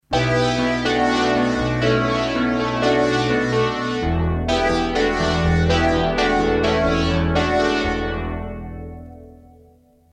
Yamaha DX7 Soundbanks
A great and unique "revival" soundbank including typical but original digital FM synthesis patches - Click here to read a detailed patches description
IMPORTANT NOTE: slight external reverb and chorus FXs have been added for the MP3 demos